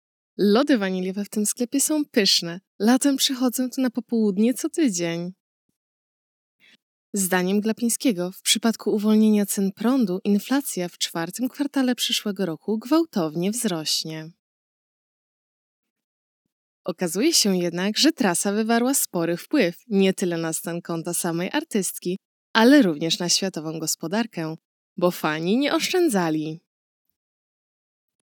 1209-TTS-Polish men and women.wav